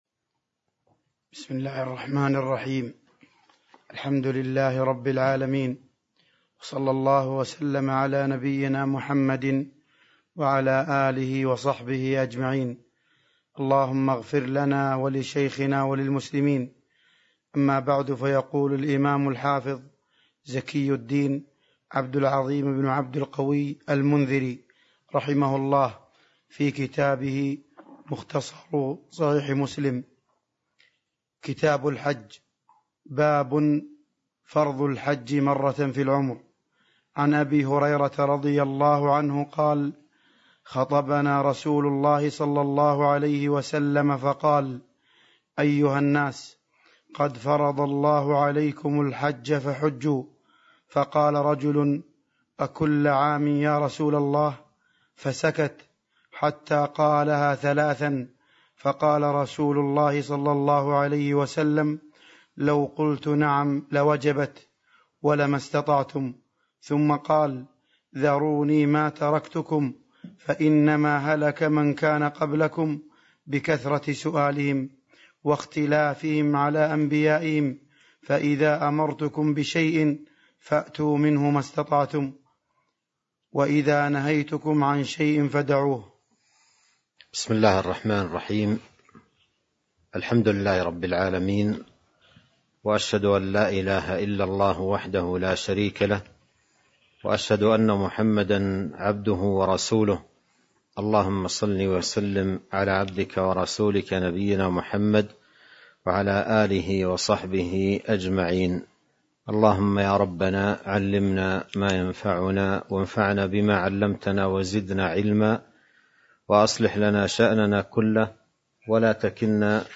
تاريخ النشر ٢٤ ذو القعدة ١٤٤٢ هـ المكان: المسجد النبوي الشيخ: فضيلة الشيخ عبد الرزاق بن عبد المحسن البدر فضيلة الشيخ عبد الرزاق بن عبد المحسن البدر باب فرض الحج مرة في العمر (01)